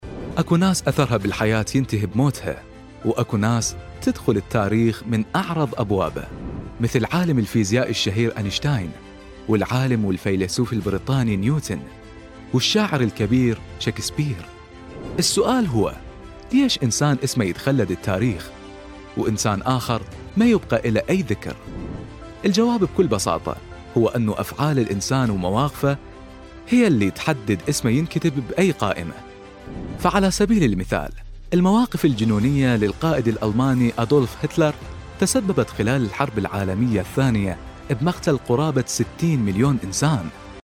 Male
Adult
Narration